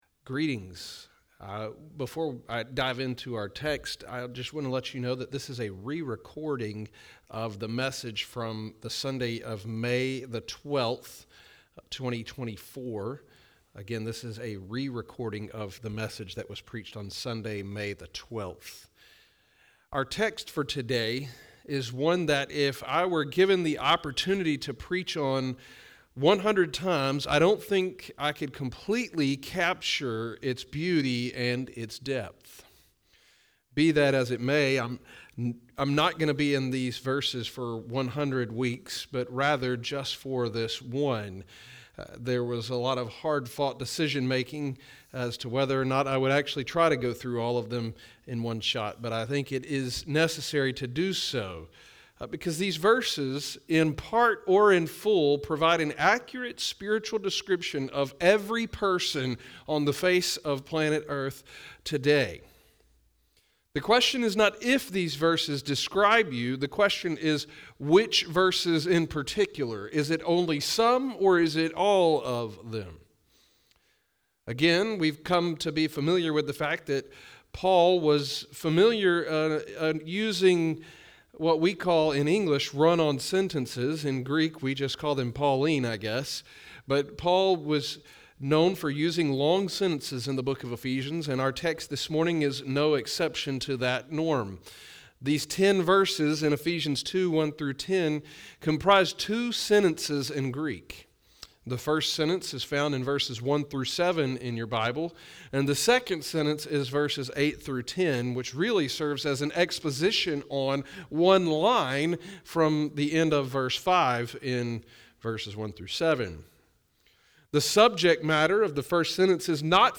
It tells the past and present of every believer, and how God did all the work to bring them to where they are today. **Note: The first portion of this sermon was re-recorded due to a technical difficulty.**